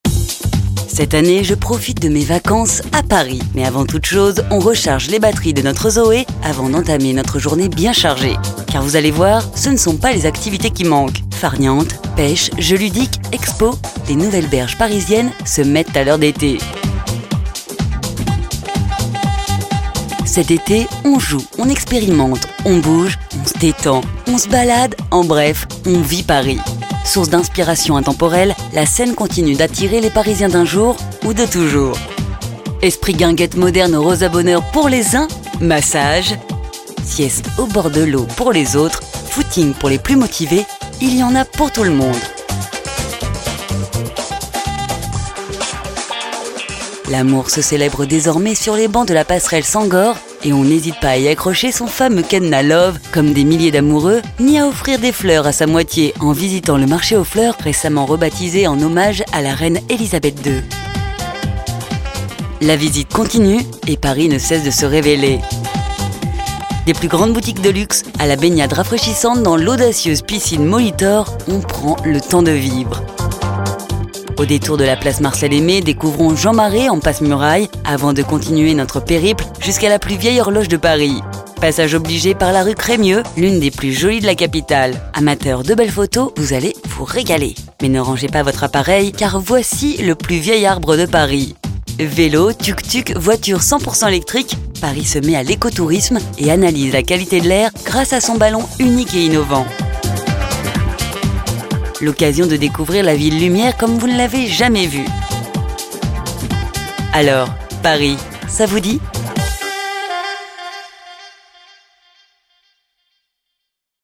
Natürlich, Zuverlässig, Erwachsene, Freundlich
Audioguide
She works from her personal studio so that your projects are recorded in the best possible quality.